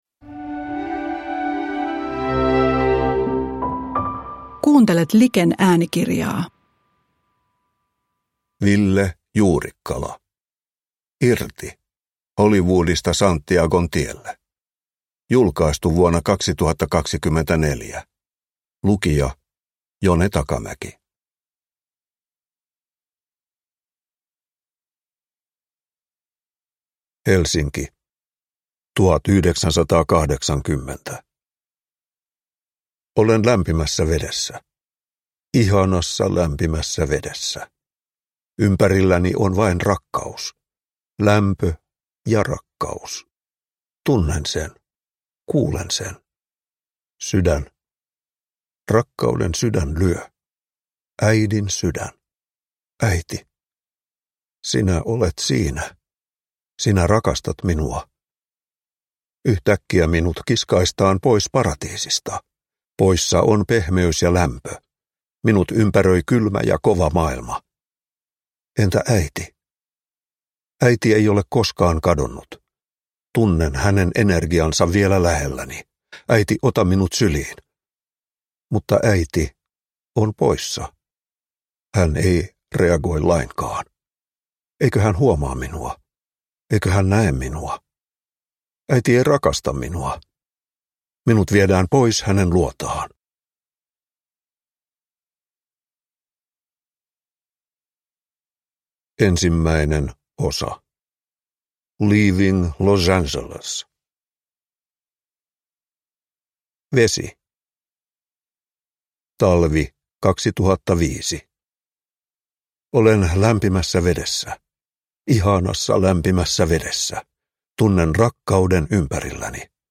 Irti (ljudbok) av Ville Juurikkala | Bokon